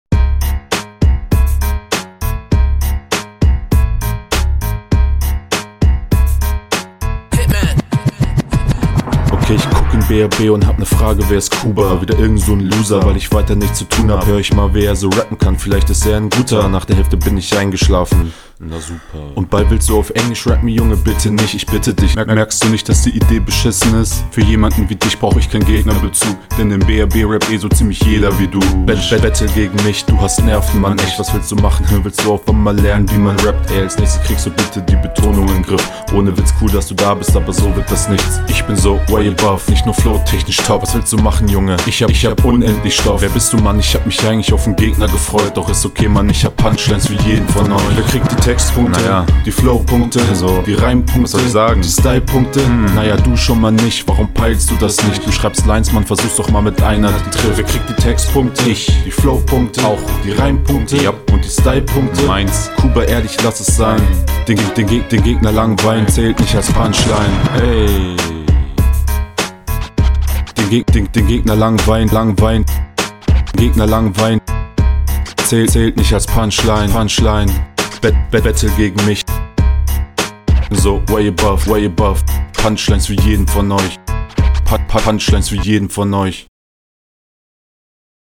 flow an sich cool aber stimme klingt noch bissl zu unausgereift und gelangweiligt. lines sind …
mag deine art ranzugehen mehr farbe in der stimme würde save auch tuff kommen